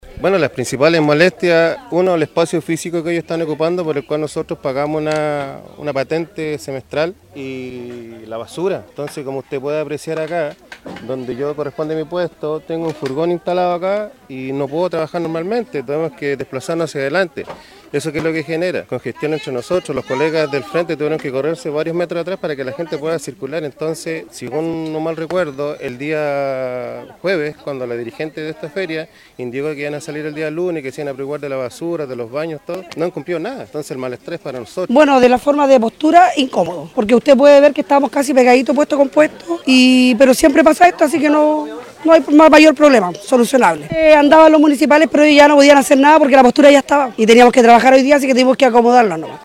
Por lo que el equipo de prensa asistió al lugar para conocer la opinión de las personas que se estaban viendo afectados por esta situación, encontrándose con ciertas diferencias  ya que para algunos era complicado trabajar así, por el poco espacio que había en el sitio, mientras que otros recalcaban que era una situación que tenía solución.